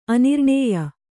♪ anirṇēya